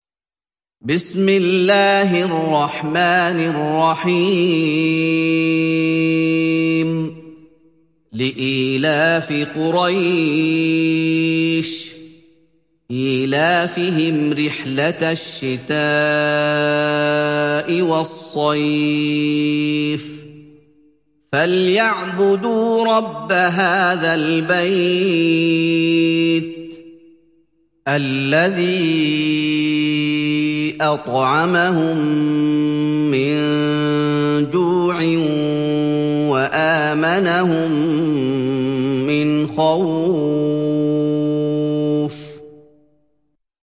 Kureyş Suresi mp3 İndir Muhammad Ayoub (Riwayat Hafs)